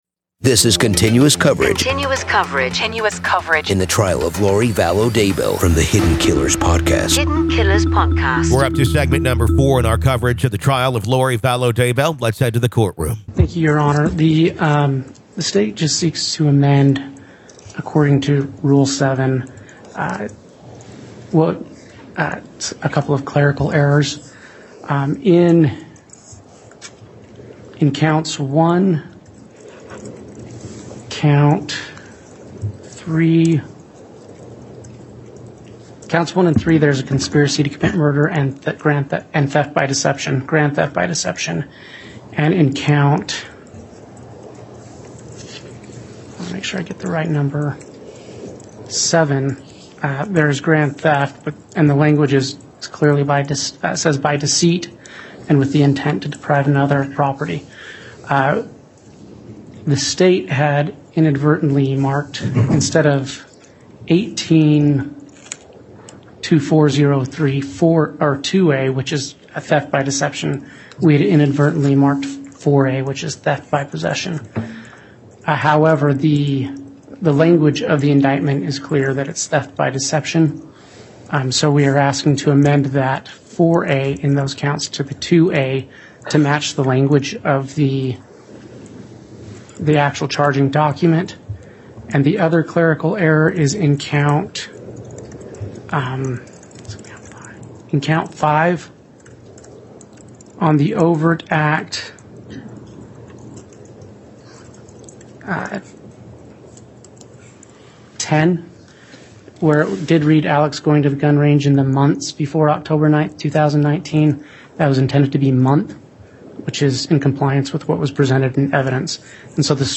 With raw courtroom audio and interviews from experts and insiders, we analyze the evidence and explore the strange religious beliefs that may have played a role in this tragic case.